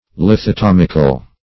Meaning of lithotomical. lithotomical synonyms, pronunciation, spelling and more from Free Dictionary.